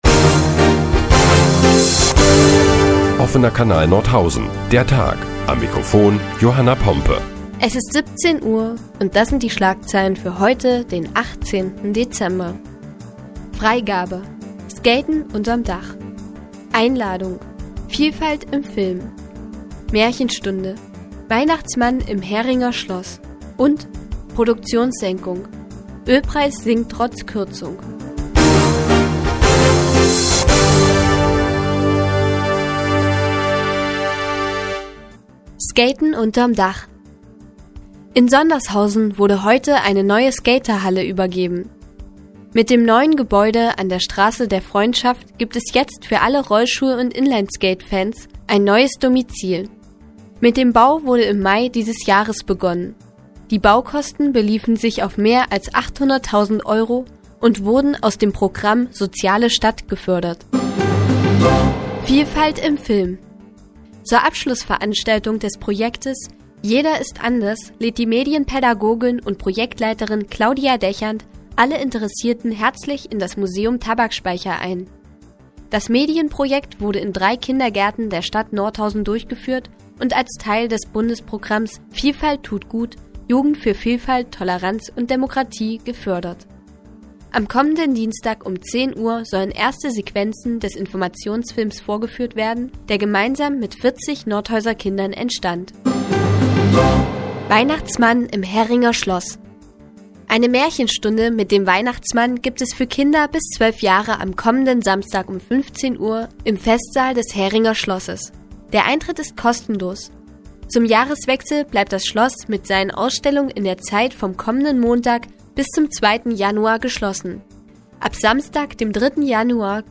Die tägliche Nachrichtensendung des OKN ist nun auch in der nnz zu hören. Heute geht es unter anderem um eine neue Skaterhalle in Sondershausen und den sinkenden Ölpreis.